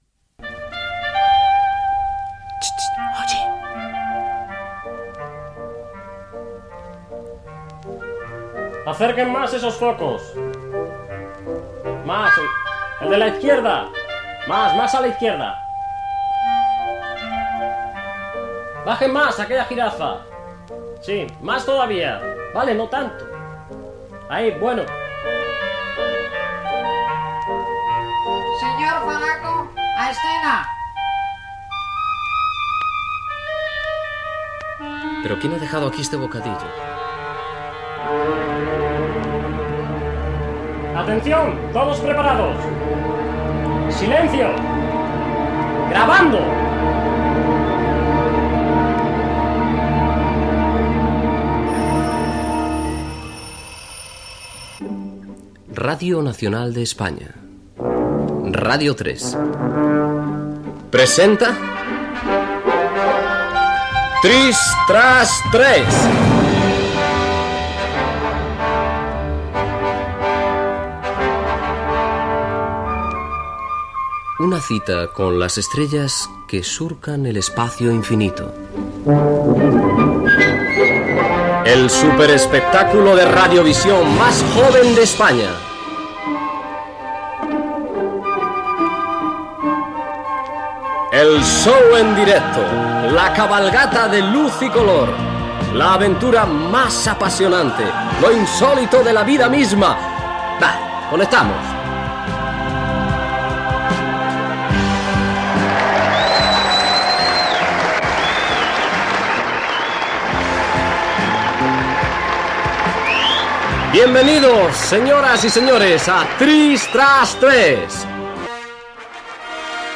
Inici del primer programa. Ambient en un plató, identificació del programa, temàtica del programa
Entreteniment